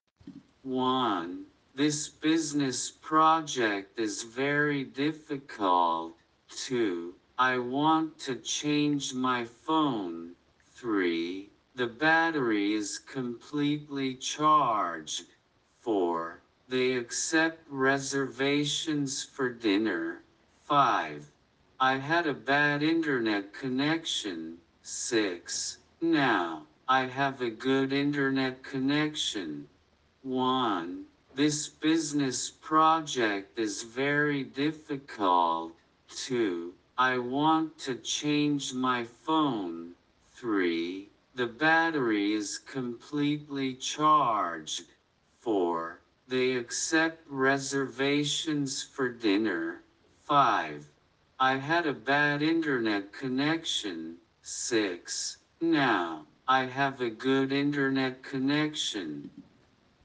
ðə ˈbætəri ɪz kəmˈplitli “ʧɑrʤd”
aɪ hæd ə bæd ˈɪntərˌnɛt kəˈnɛkʃən